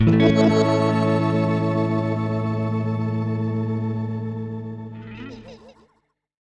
GUITARFX 4-R.wav